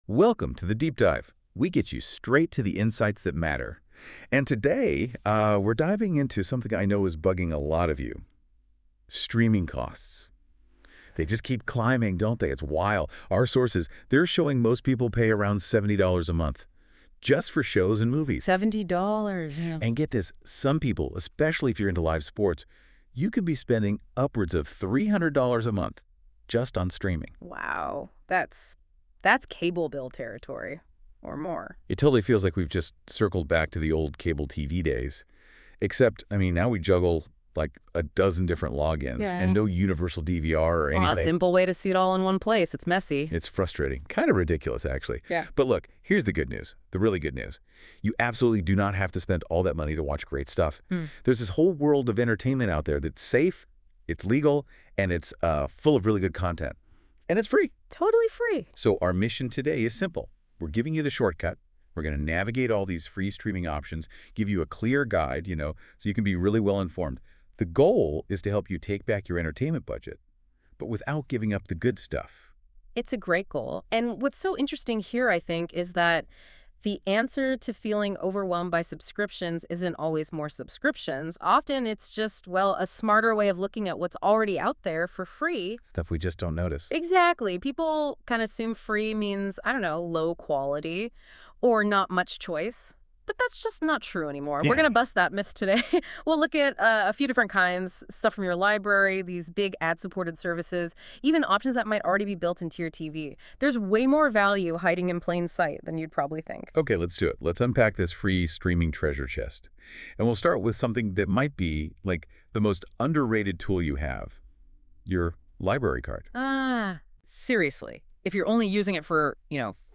By-the-way, click these links if you would like to hear a 15-minute podcast created by AI or a 4-minute slide presentation both create by AI using Google’s NotebookLM.